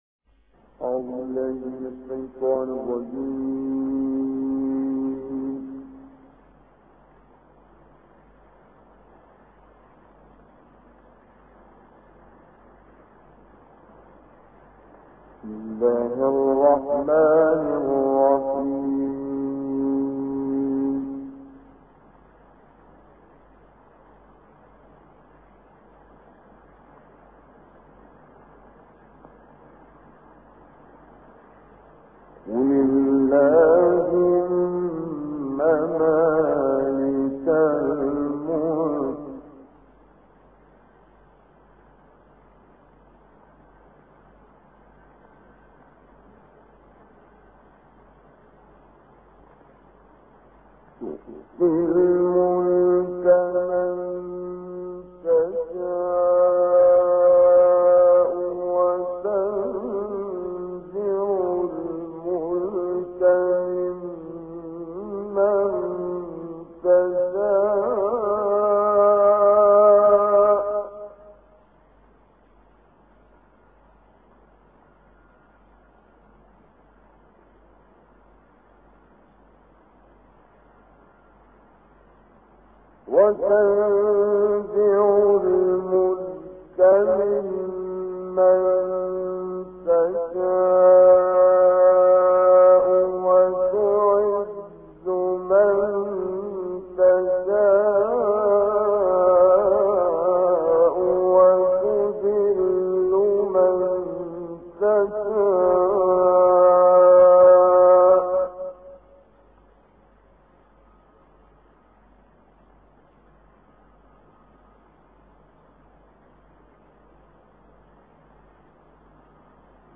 Reciter Kamel Yousf El Behteemy